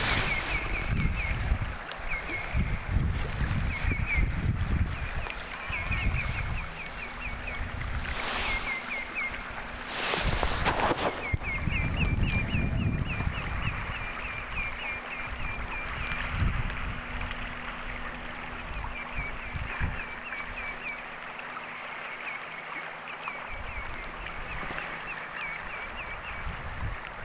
valkoviklot ääntelevät
valkoviklojen_aantelya.mp3